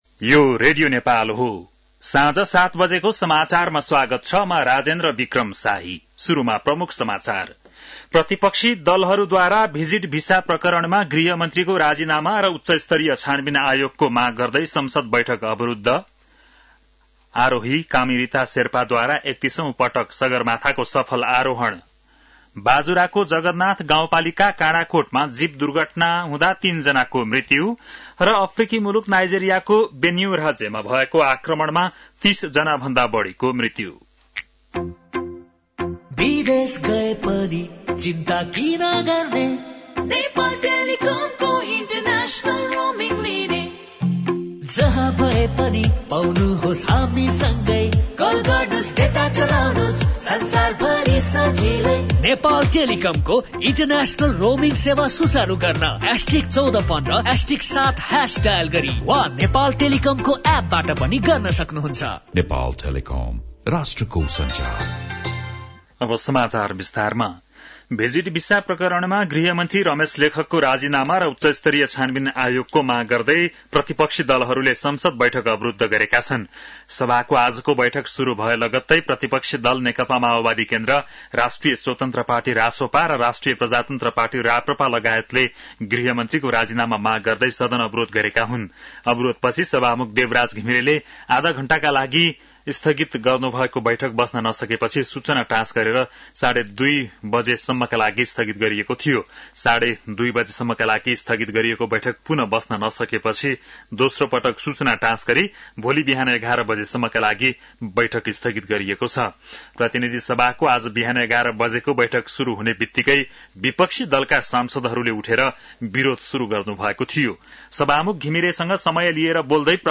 बेलुकी ७ बजेको नेपाली समाचार : १३ जेठ , २०८२